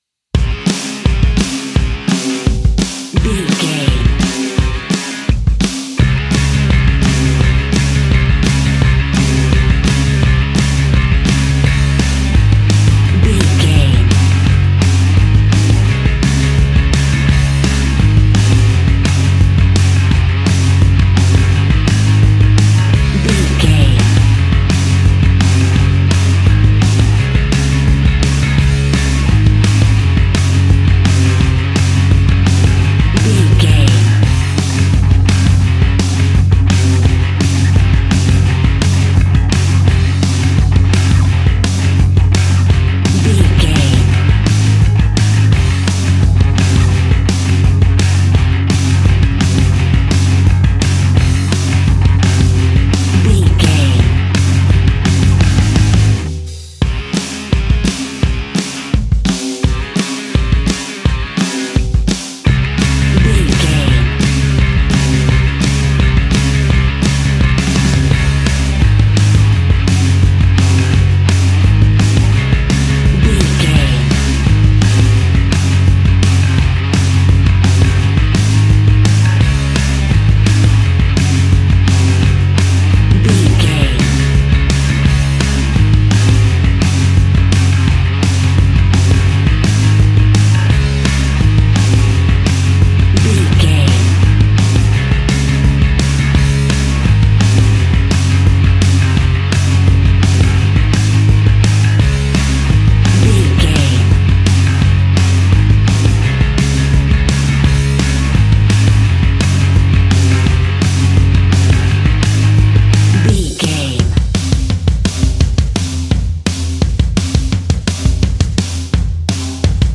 Uplifting
Ionian/Major
D♭
hard rock
distortion
punk metal
instrumentals
Rock Bass
heavy drums
distorted guitars
hammond organ